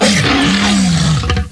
Animal sounds
Growl(33 kb, .wav)
growl.wav